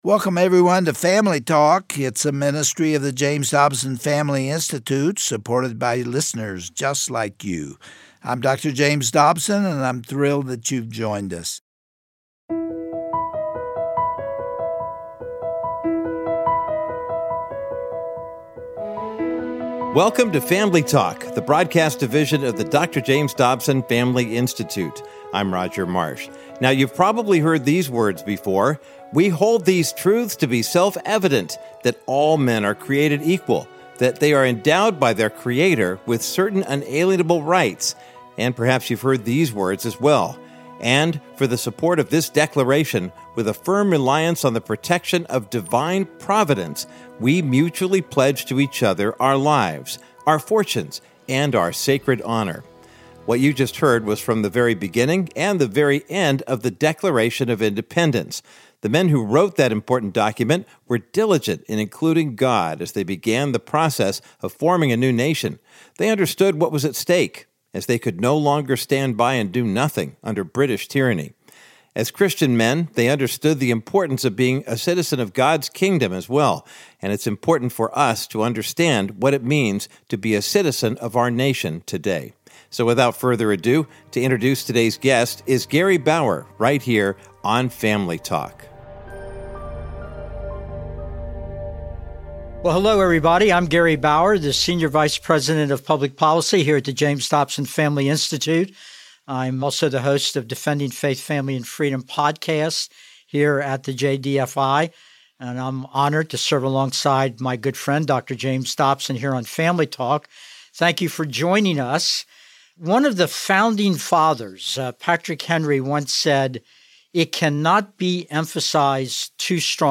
On today’s edition of Family Talk, Gary Bauer interviews Tony Perkins, president of the Family Research Council. Tony shares that now is the time for Christians to stand firm in our faith as the enemy seeks to separate us from our source of strength, the Word of God.